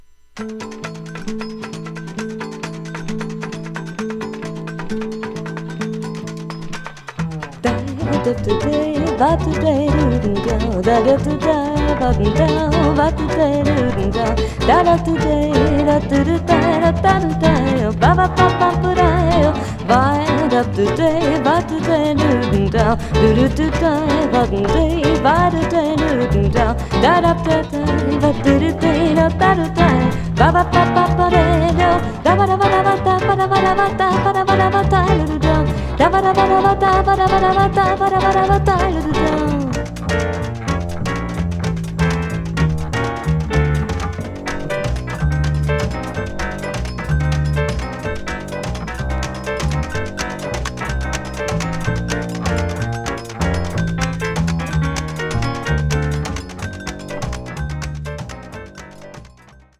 大推薦レア・ドイツ産ブラジリアン・アルバム！！！